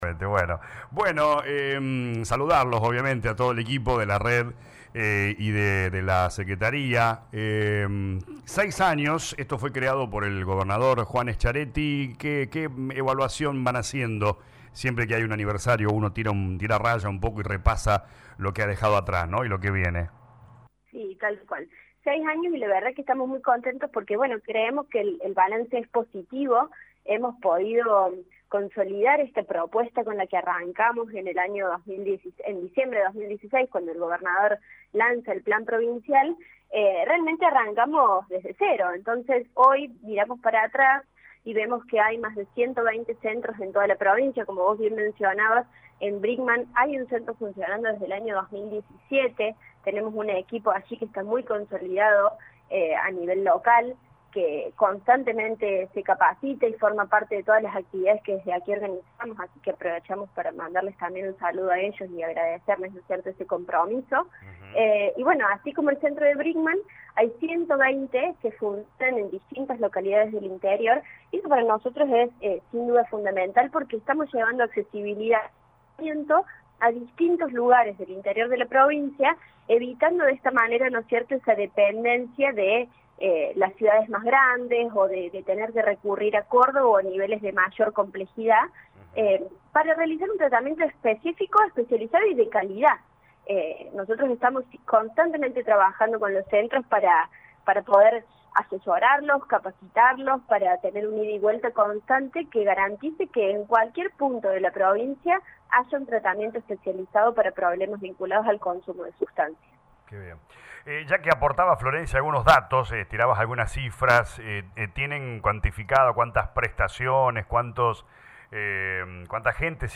En diálogo con LA RADIO 102.9, la subsecretaria de Prevención y Asistencia de las Adicciones, Florencia Maiocco repasó que dicho plan lanzado en 2016 por el gobernador Juan Schiaretti proponiendo una política pública de ampliación de las redes de contención, prevención y asistencia; descentralización de los dispositivos de consulta y atención, además de una formación permanente de los recursos humanos.